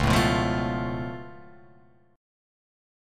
Db+7 chord